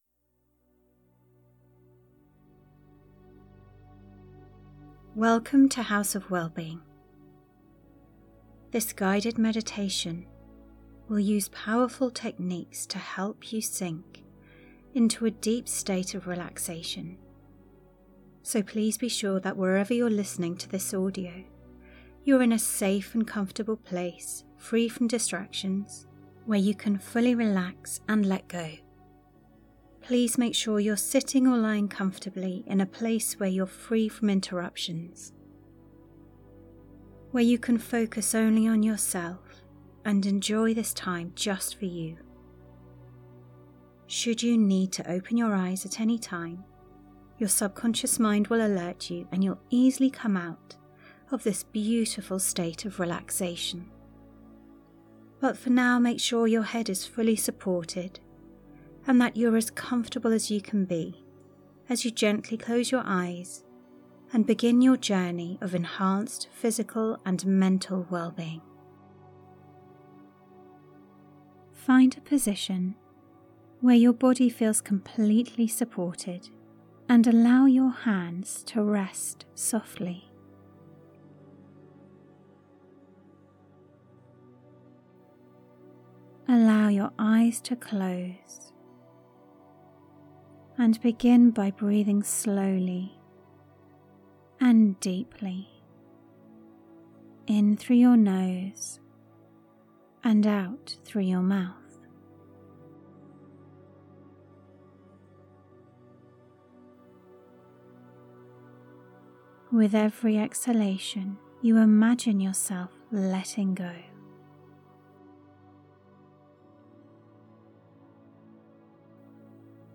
This Anxiety Release Acupressure meditation is a soothing audio that combines breath awareness and gentle acupressure on the ears, chest, hands, and crown of the head to ease anxiety and calm the nervous system. With supportive affirmations, it helps you let go of worry, restore balance, and feel safe, grounded, and at peace.